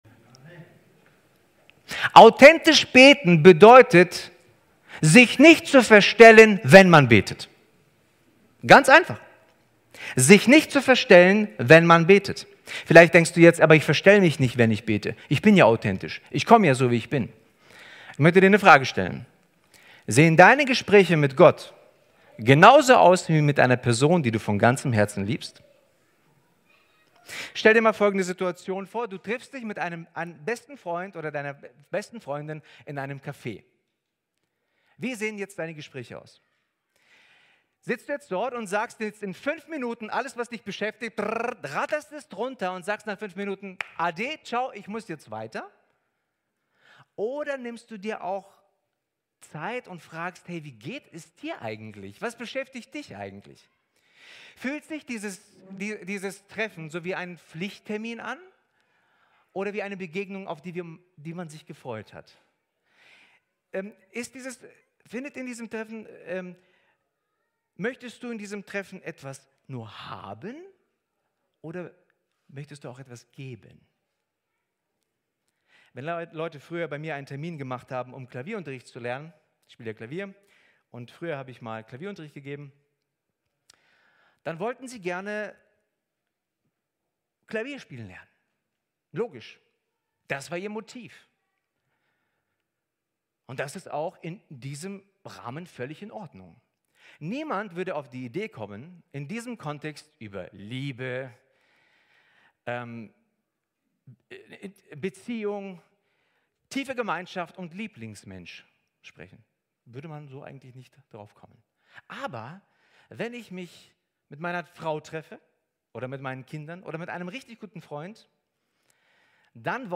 Aufgrund eines technischen Problems gab es leider Fehler beim Ton. Dadurch fehlen die ersten fünf Minuten der Predigt.